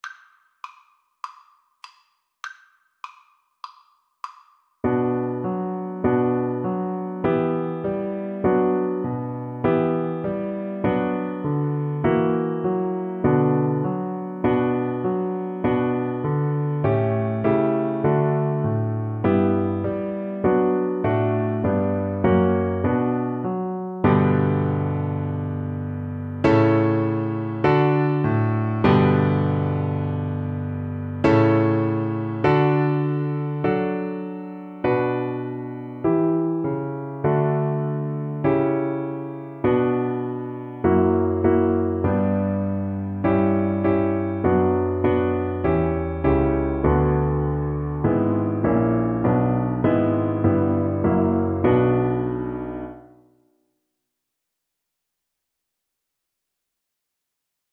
B4-B5
4/4 (View more 4/4 Music)
Espressivo